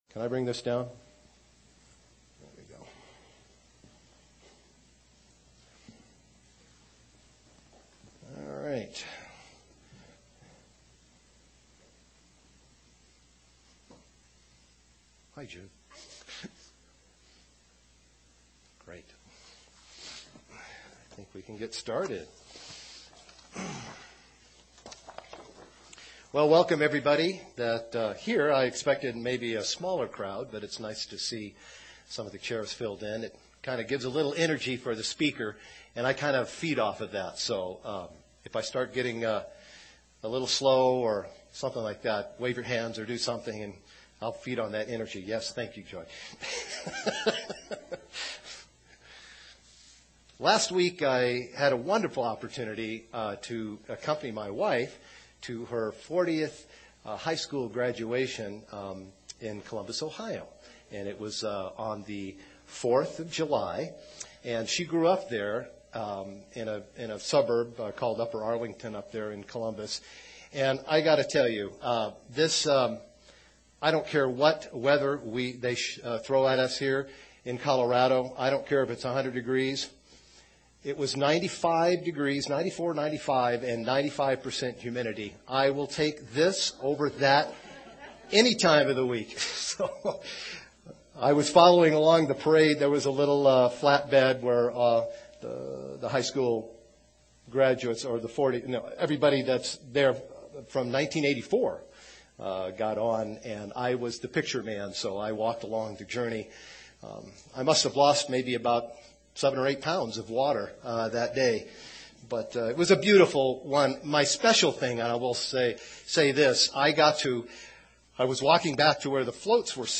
In this sermon, the example of an anchor is used by describing it's many forms to stabilize an object. As Christians, we need to use the bible to anchor us to God.